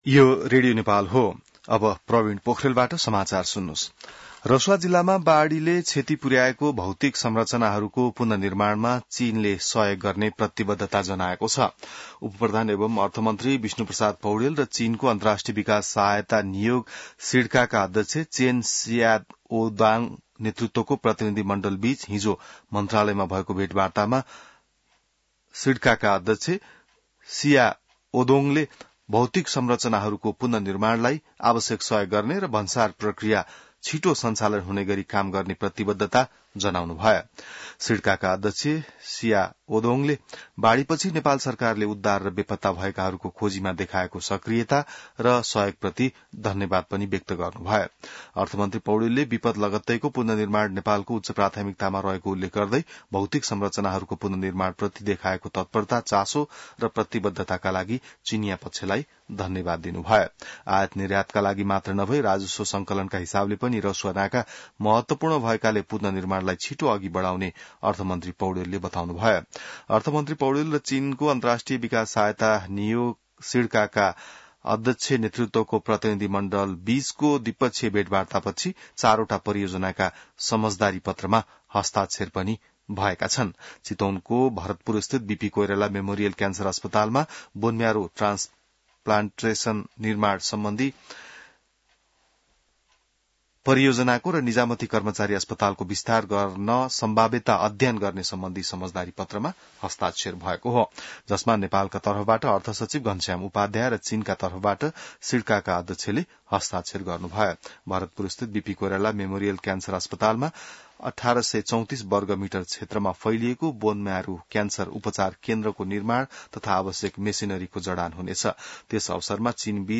An online outlet of Nepal's national radio broadcaster
बिहान ६ बजेको नेपाली समाचार : ६ साउन , २०८२